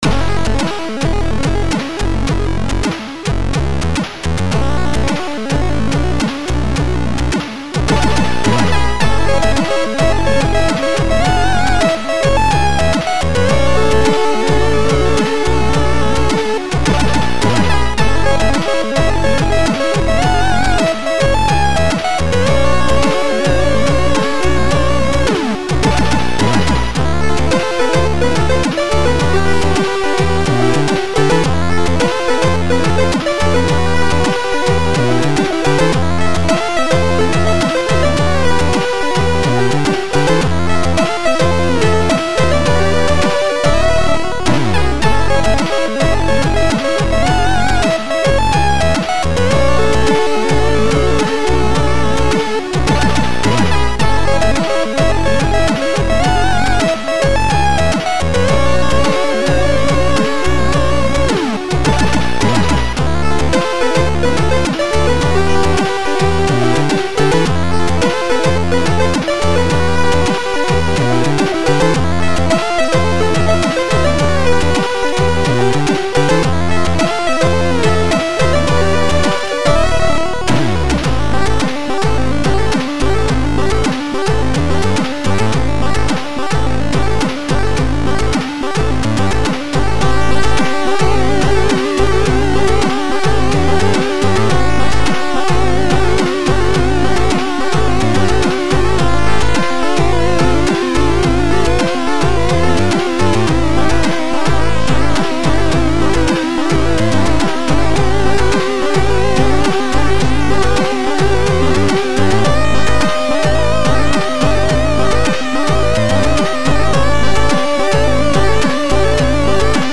AHX v2